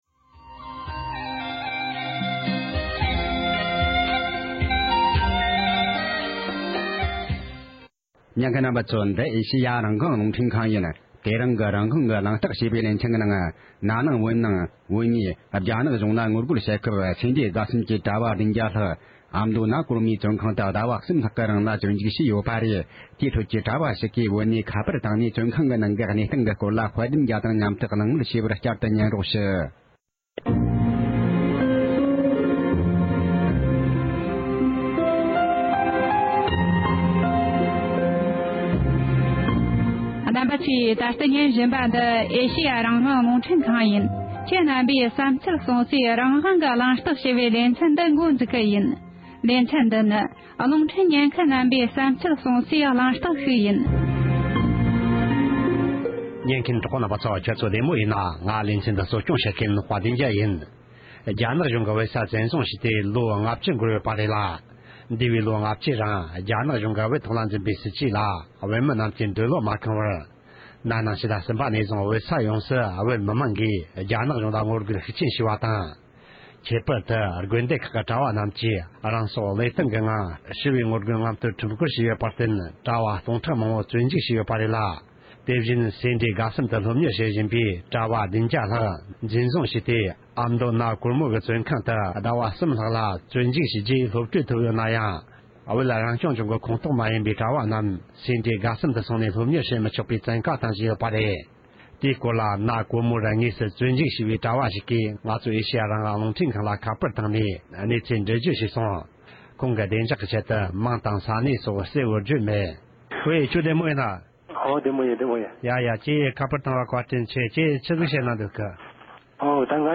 བོད་ནས་ཁ་པར་བརྒྱུད